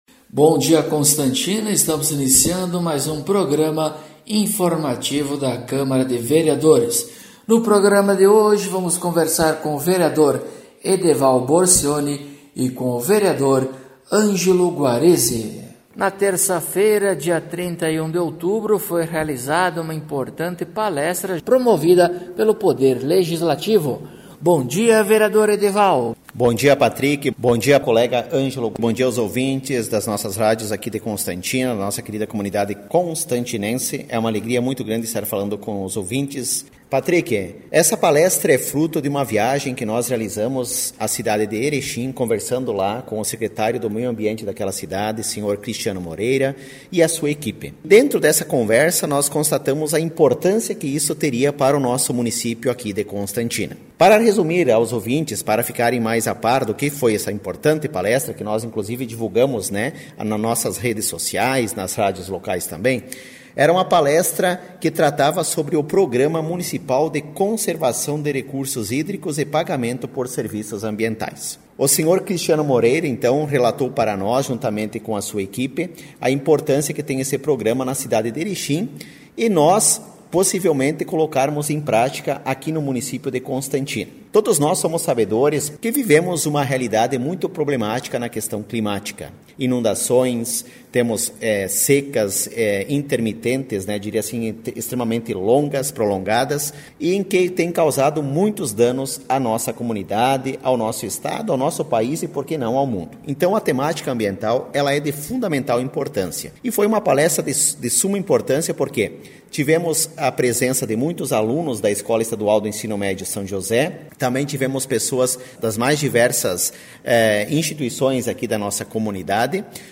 Acompanhe o programa informativo da câmara de vereadores de Constantina com o Vereador Edeval Borcioni e o Vereador Angelo Guarezi.